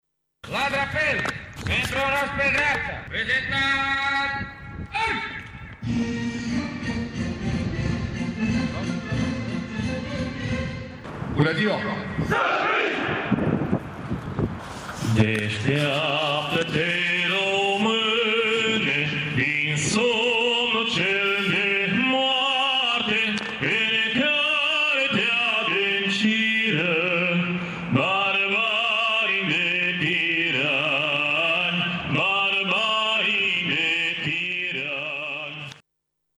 Festivităţile ce au marcat Ziua Jandarmeriei au avut loc în această dimineaţă la Inspectoratul de Jandarmi din Tîrgu-Mureş.